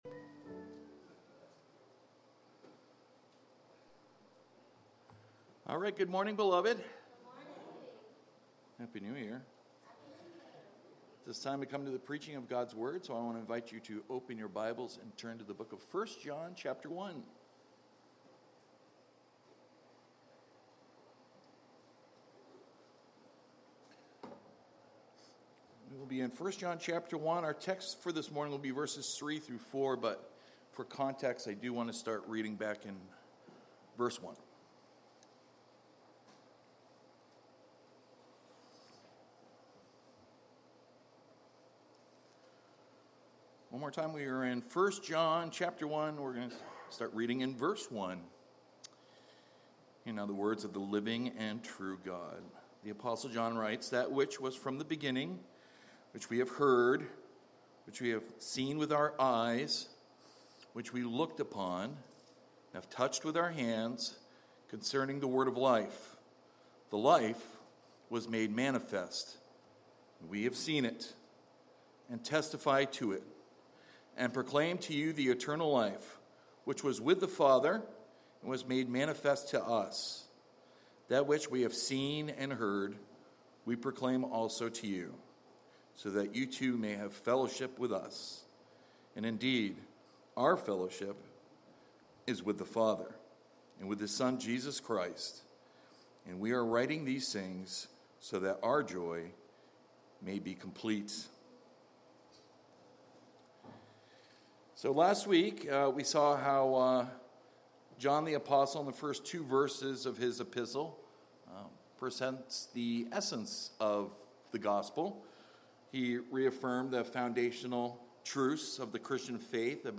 Sermons by At the Cross … continue reading 352 episodes # Religion # Christianity # Cross # I Believe